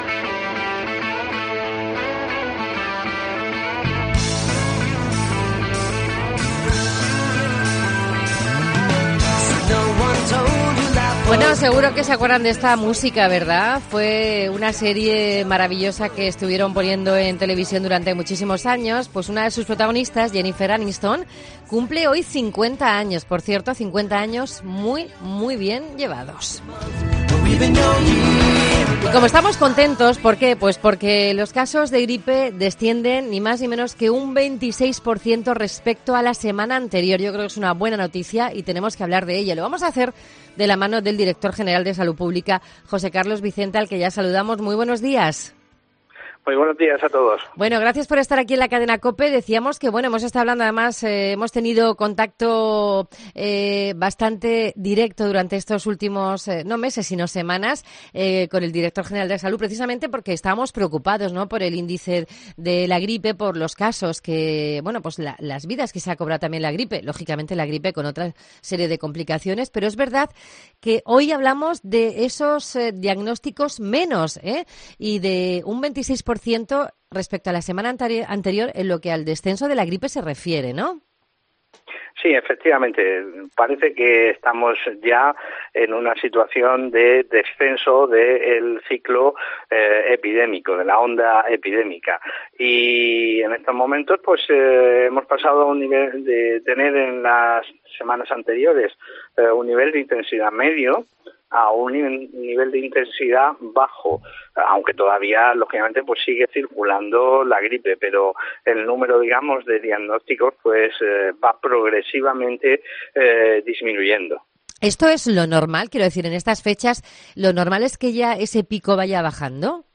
Actualmente hay 153 casos de gripe por cada cien mil habitantes.  Lo ha contado en COPE Murcia, el director de salud púbblica, José Carlos Vicente.